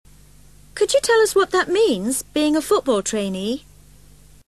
PITCH IN BRITISH ENGLISH
After listening to each audio file, repeat it aloud trying to imitate the intonation:
REPORTER TO STRANGER TO APPEAR POLITE